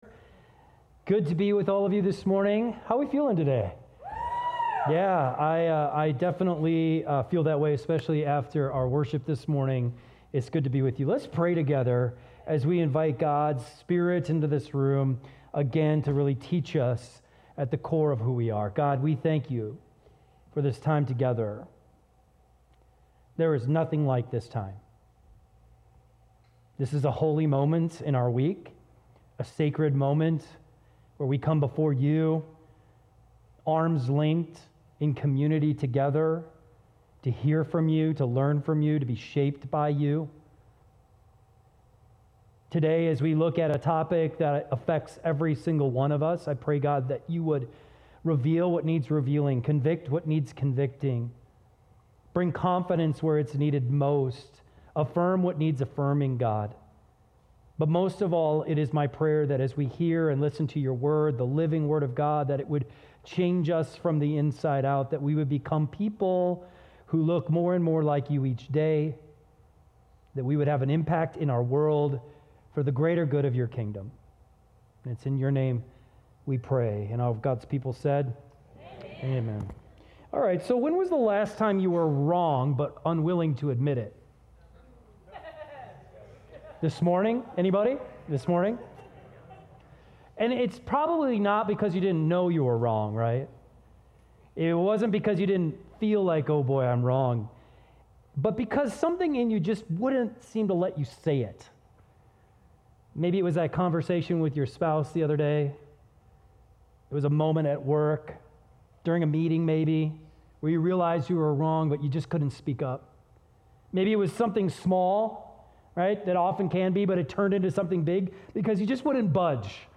keyboard_arrow_left Sermons / Give It Up Series Download MP3